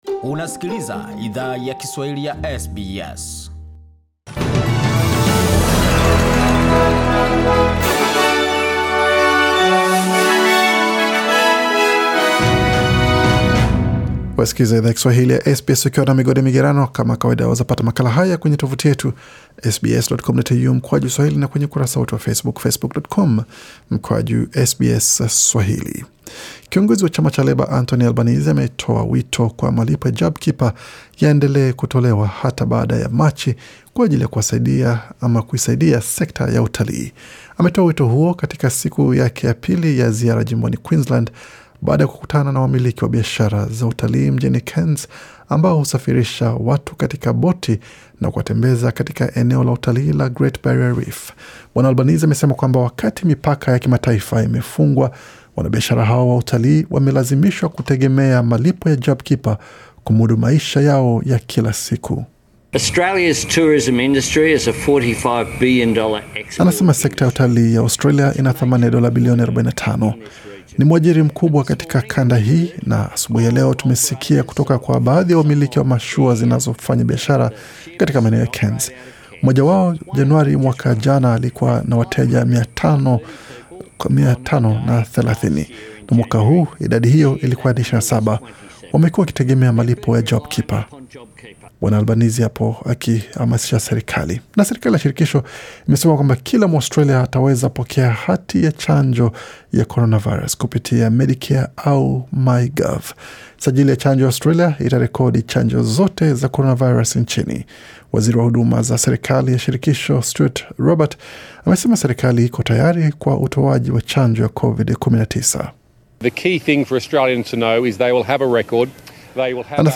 Taarifa ya habari 7 Februari 2021